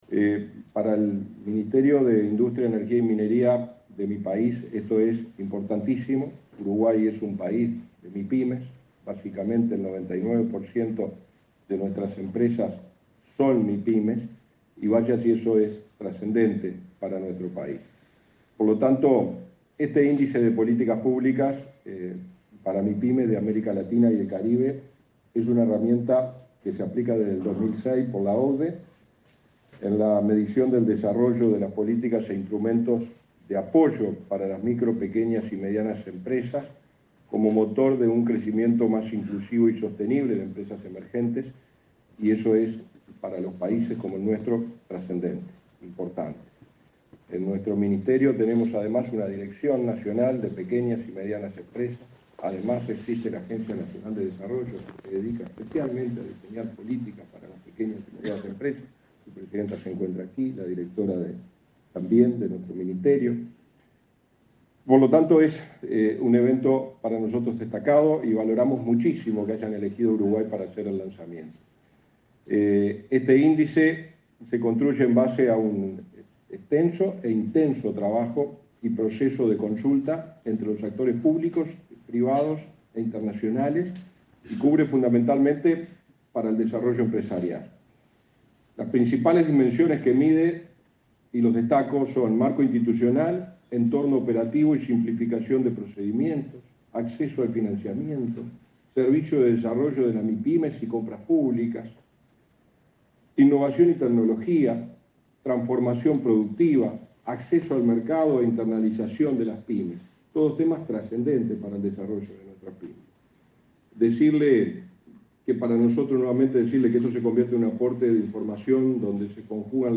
Palabras del subsecretario de Industria, Walter Verri
El subsecretario de Industria, Walter Verri, participó en el lanzamiento de la segunda edición del índice de políticas públicas para mipymes en